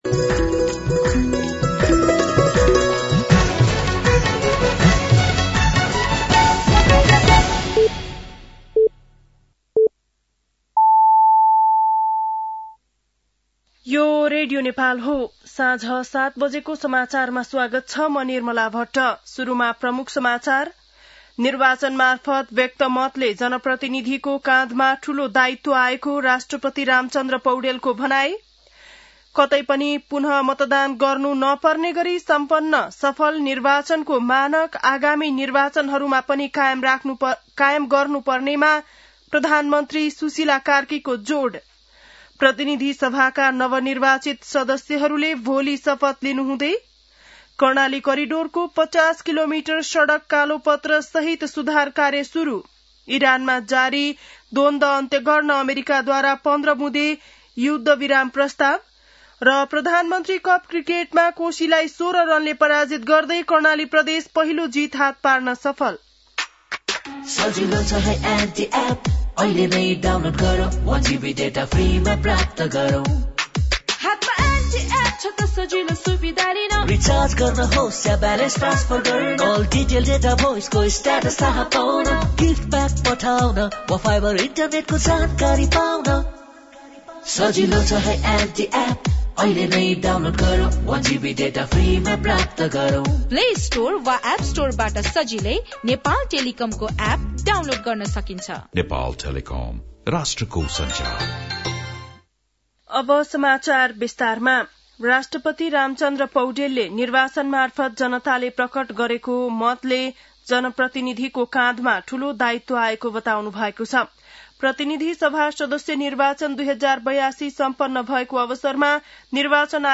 बेलुकी ७ बजेको नेपाली समाचार : ११ चैत , २०८२